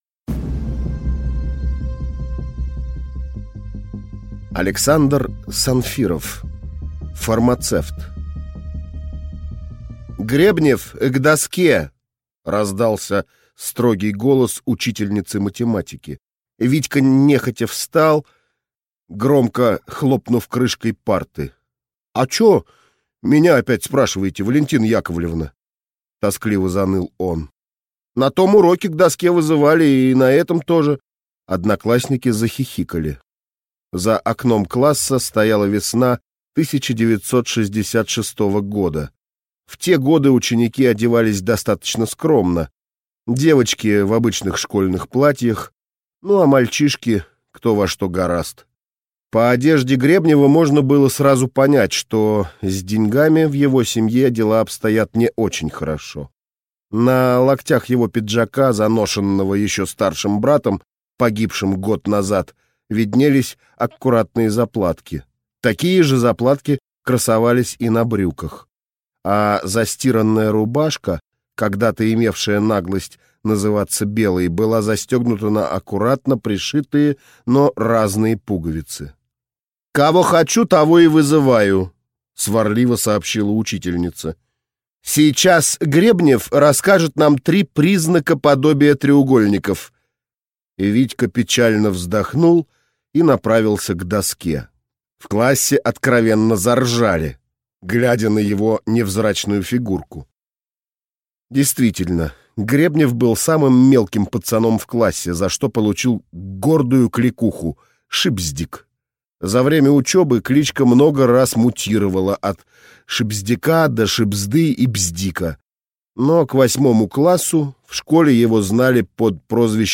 Aудиокнига Фармацевт